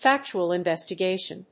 factual+investigation.mp3